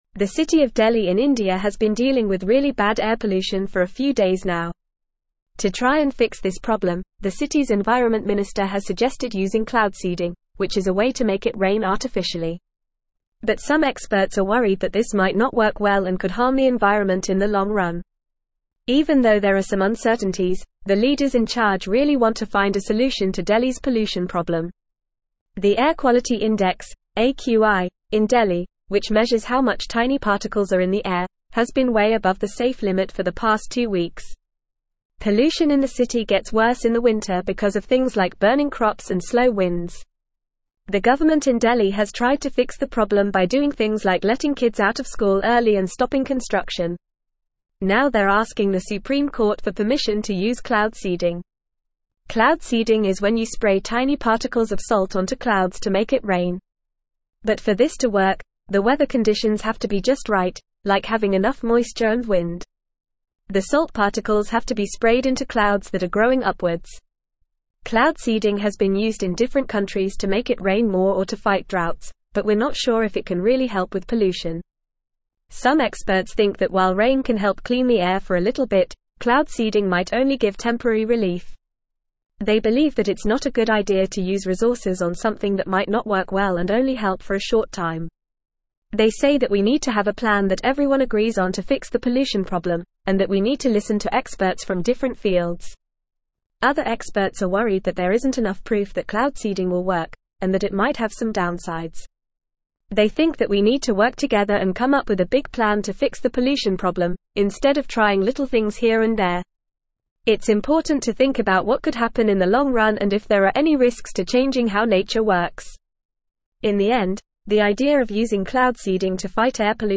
Fast
English-Newsroom-Upper-Intermediate-FAST-Reading-Delhi-Considers-Cloud-Seeding-to-Combat-Air-Pollution.mp3